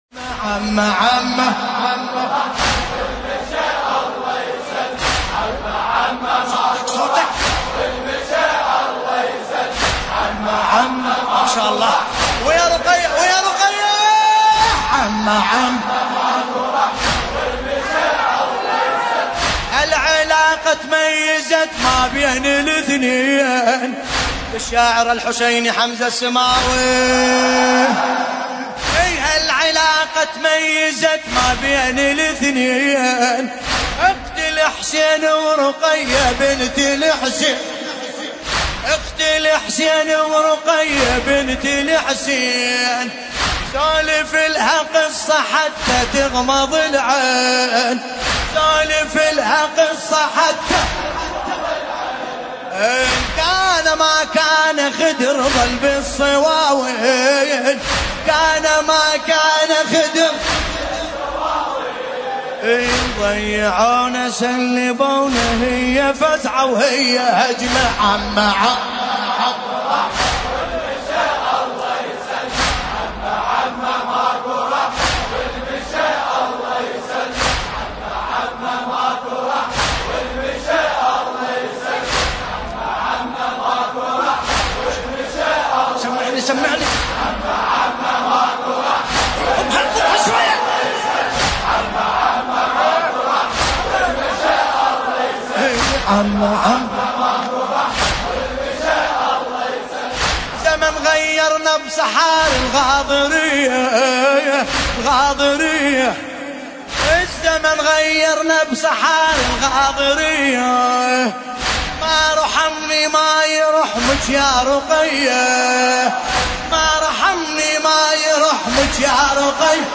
ملف صوتی عمه عمه بصوت باسم الكربلائي
قصيدة : عمه عمهللشاعر : حمزة السماوي المناسبة : رثاء السيدة رقية(ع)ليلة 5 صفر 1440 | آل بلاغي